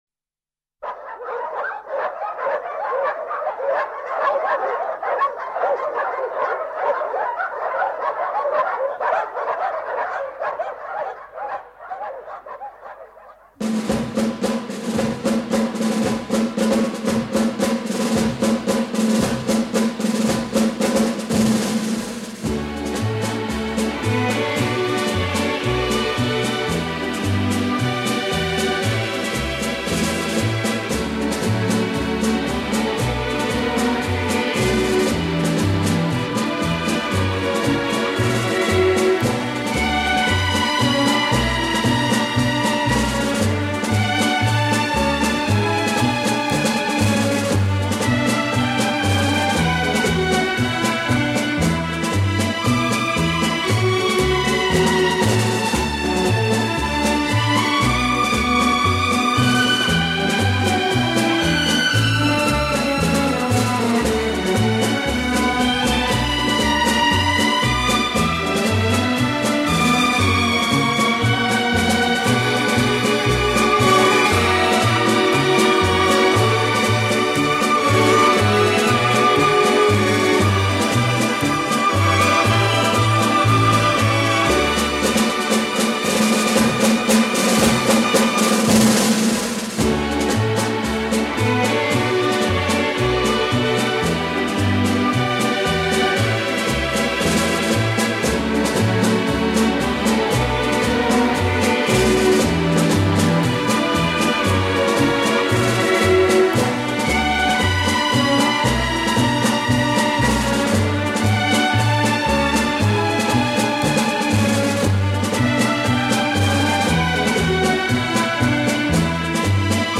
1962   Genre: Soundtrack   Artist